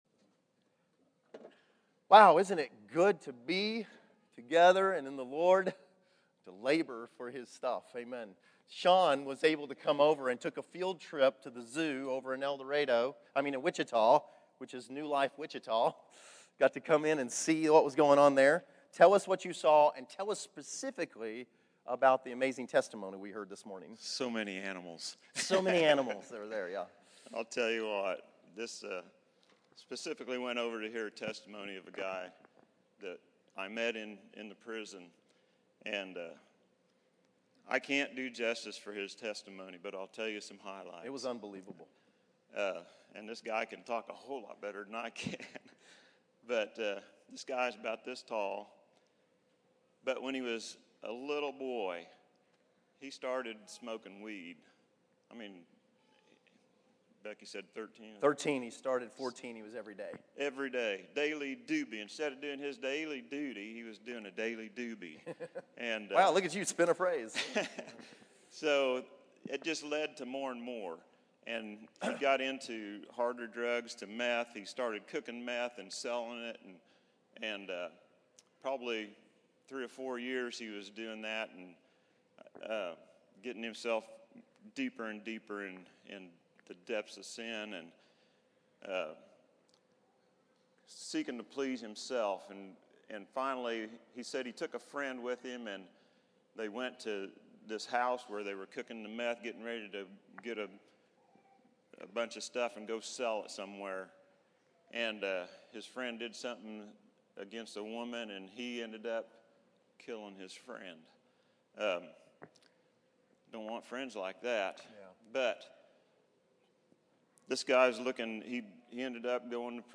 Testimonies      |      Location: El Dorado